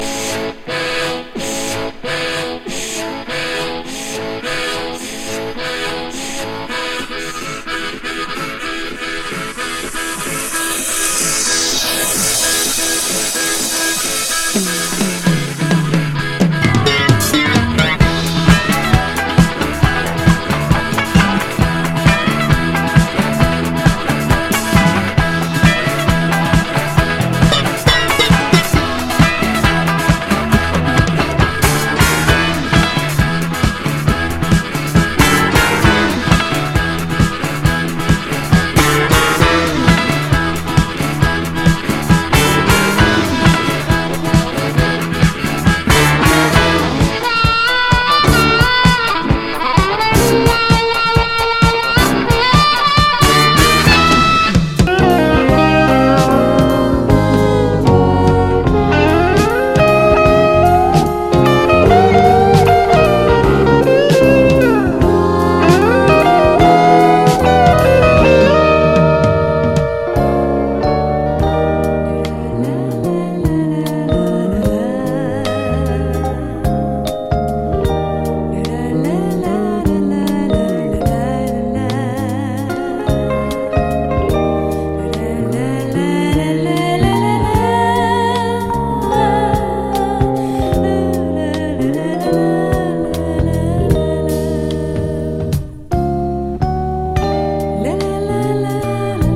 イージーレゲー
カリフォルニア録音
ウェストコースト・グルーブ歌謡
トロピカル・テクノ・ハワイアン歌謡
ロッキン・グルーブ